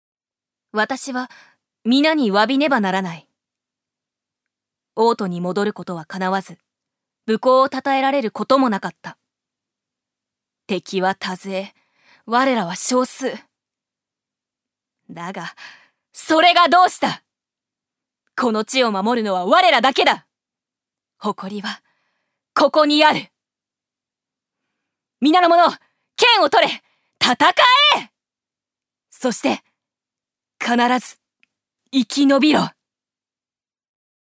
預かり：女性
セリフ３